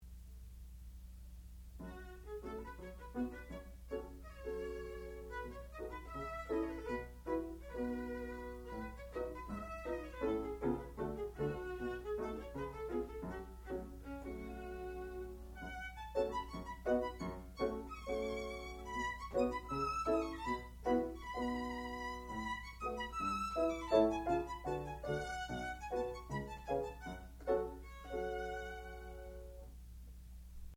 sound recording-musical
classical music
violin
piano
Advanced Recital